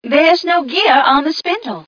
1 channel
mission_voice_t9ca006.mp3